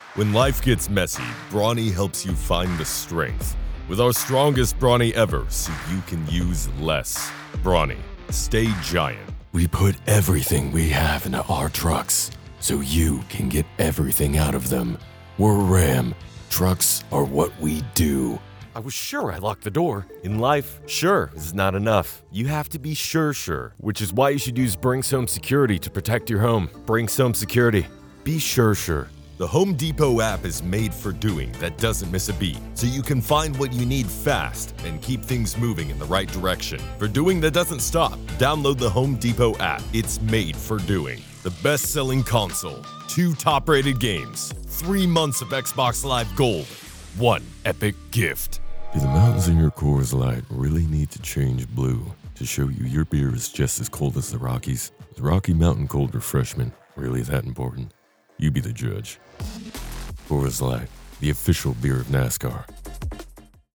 Male
Deep, authentic, engaging and warm male voice, able to bring both the hype and energy of a high-octane commercial promo and the intimacy of an emotionally charged, empathetic character read.
Radio Commercials
5 Commercial/Promo Reads
Words that describe my voice are Deep, Engaging, Authentic.